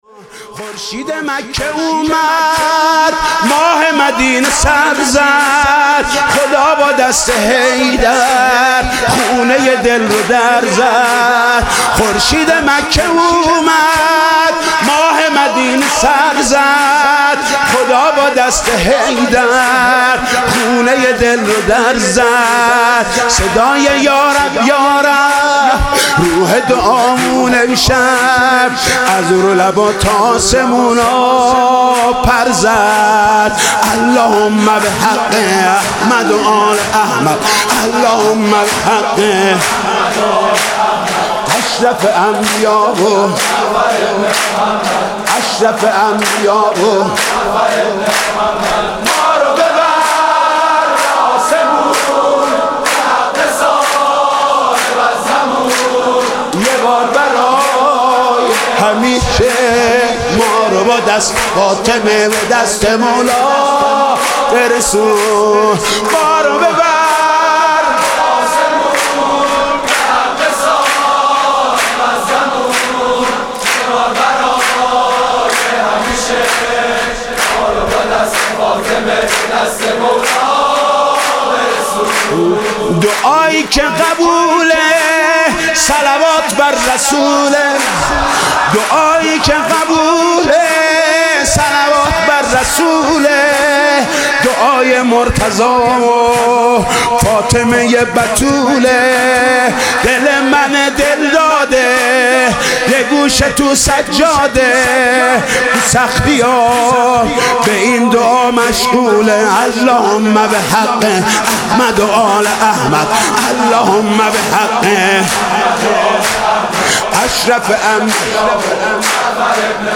سرود: خورشید مکه اومد، ماه مدینه پر زد